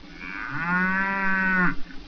Click on the squares in the barn and identify the sound of the animal.